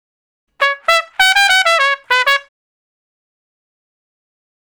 087 Trump Straight (Db) 11.wav